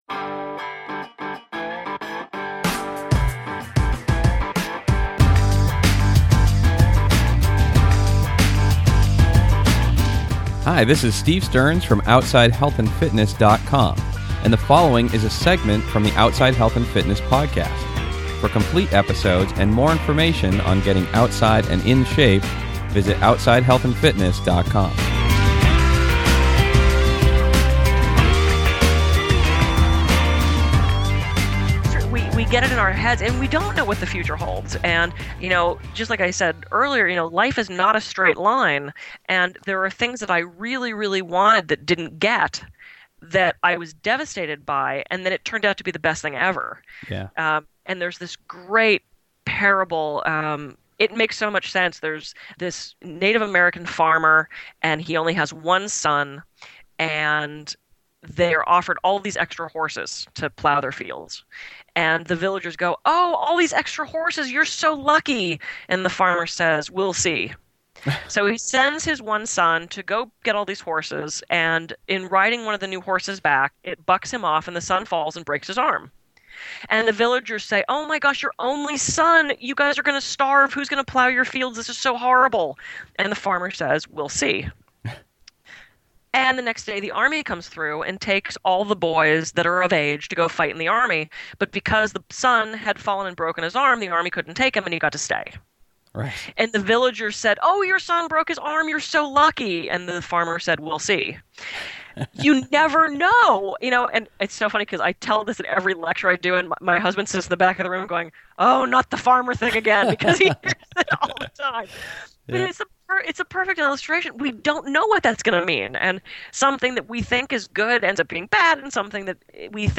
In this audio brief from my upcoming interview